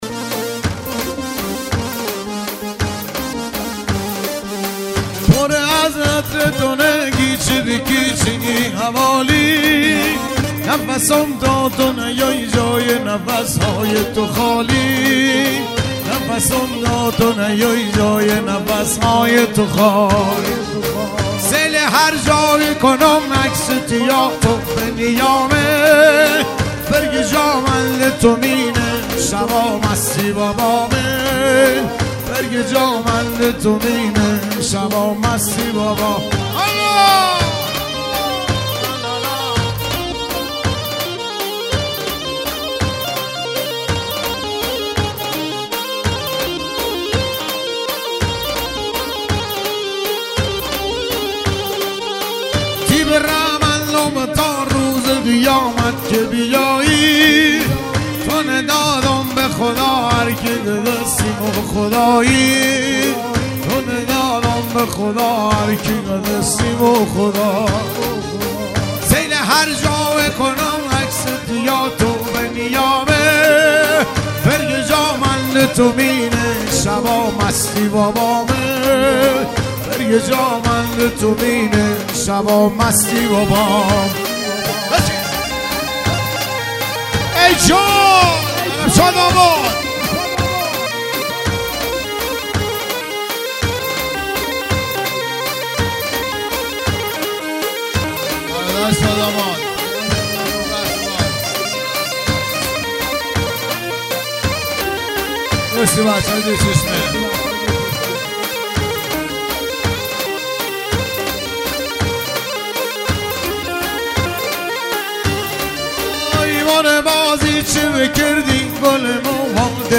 غمگین محلی لری